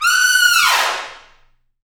Index of /90_sSampleCDs/Roland L-CDX-03 Disk 2/BRS_Tpts FX menu/BRS_Tps Falls